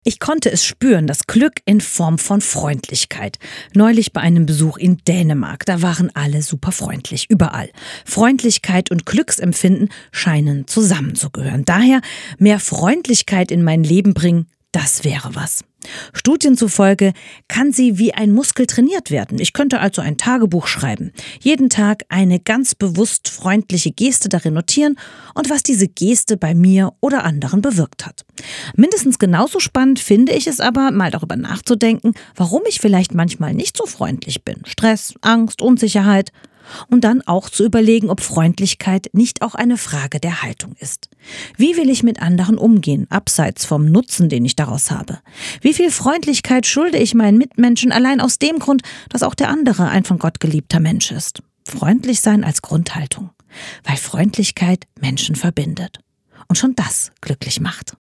Startseite > andacht > Uh…